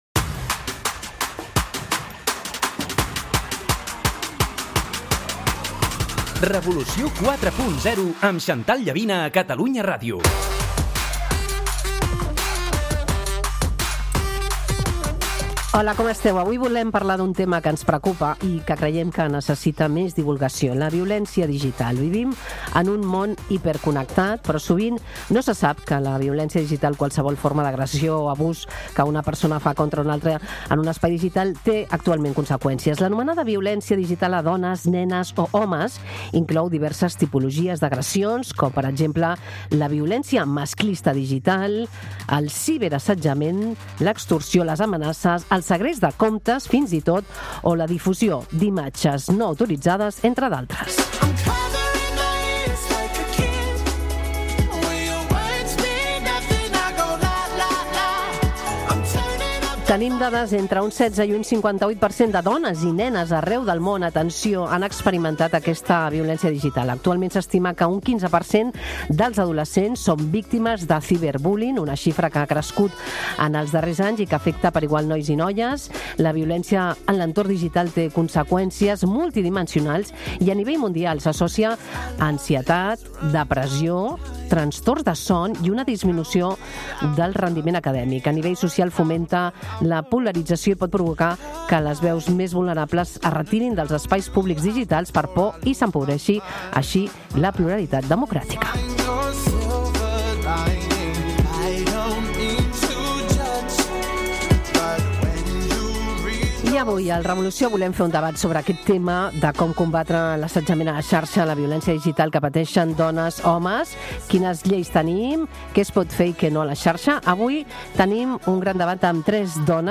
dedica un debat a la violència digital, amb expertes del món tecnològic i jurídic